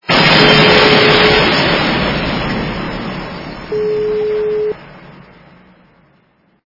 » Звуки » другие » Звук - Взрыв Большой Бомбы В Продуктовом Ларьке
При прослушивании Звук - Взрыв Большой Бомбы В Продуктовом Ларьке качество понижено и присутствуют гудки.